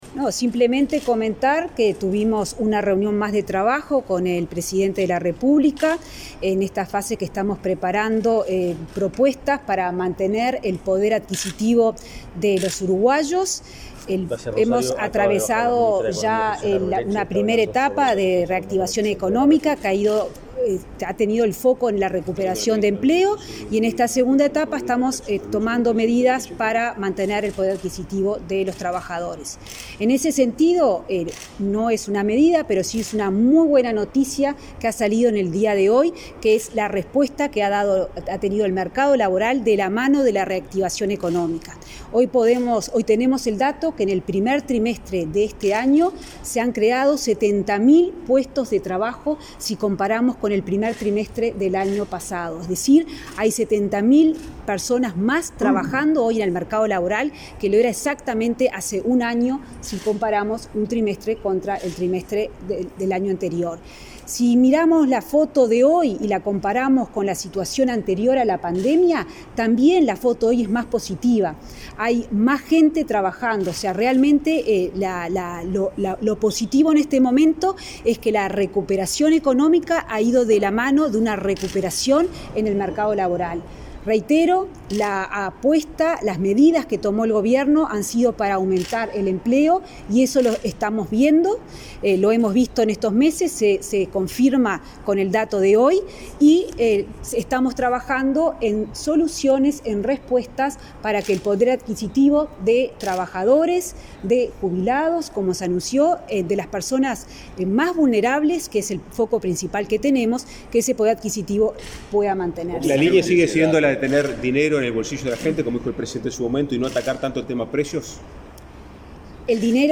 Declaraciones a la prensa de la ministra de Economía y Finanzas, Azucena Arbeleche
Declaraciones a la prensa de la ministra de Economía y Finanzas, Azucena Arbeleche 05/05/2022 Compartir Facebook X Copiar enlace WhatsApp LinkedIn Tras participar en una reunión de trabajo con el presidente de la República, Luis Lacalle Pou, este 5 de mayo, la ministra Azucena Arbeleche efectuó declaraciones a la prensa.